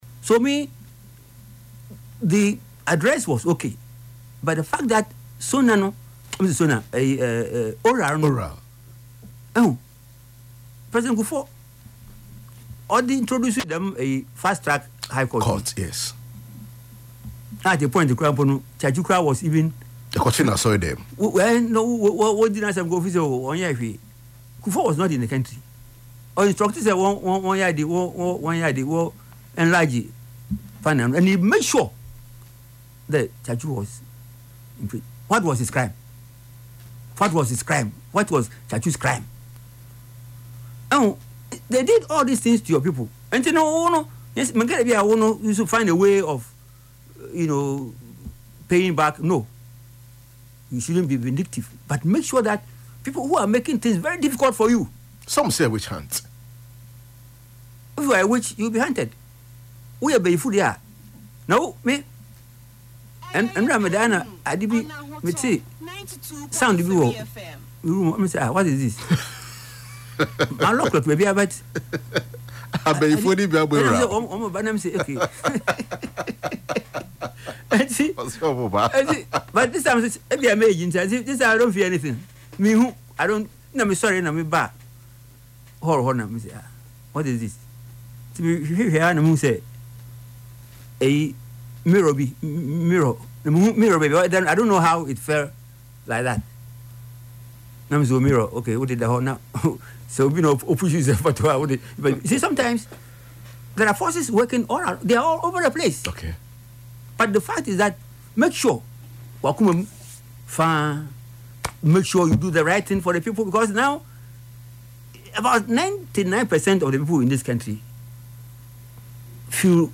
Speaking on Ahotor FM’s Yepe Ahunu show on Saturday